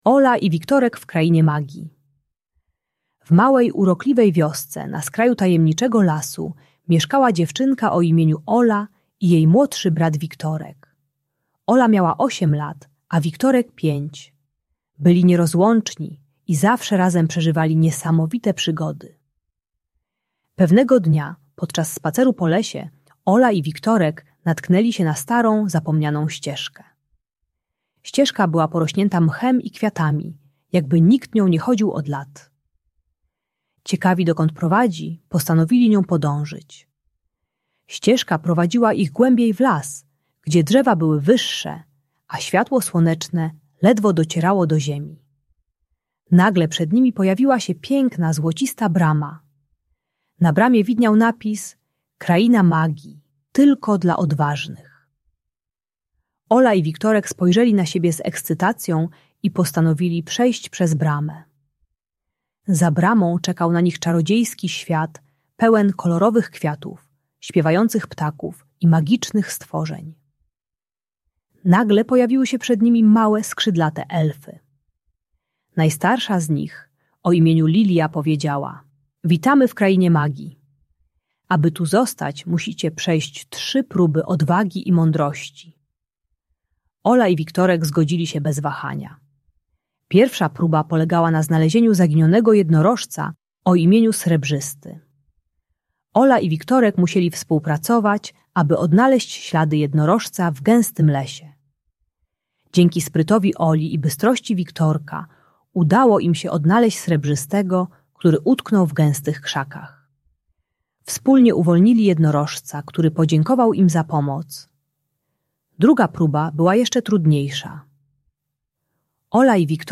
Kraina Magii - Rodzeństwo | Audiobajka